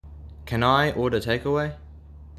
ネィティヴの音声を録音したので、雰囲気を感じて下さいね。